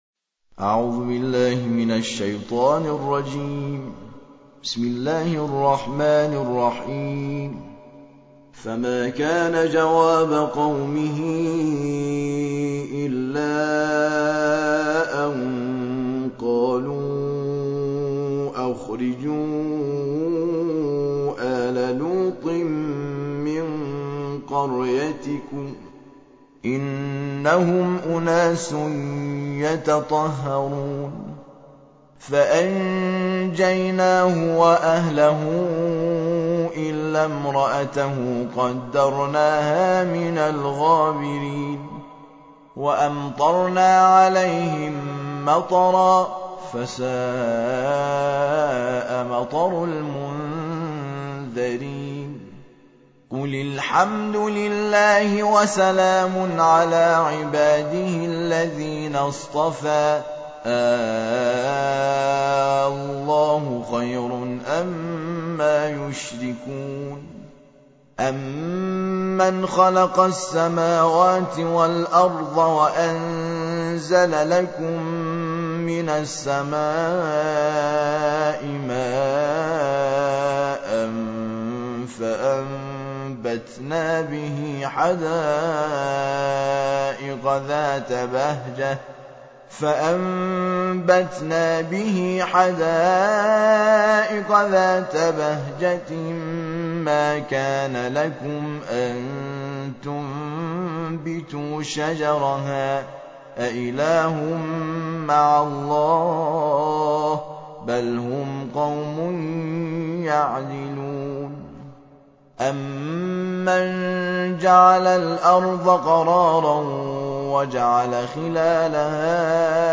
الجزء العشرون / القارئ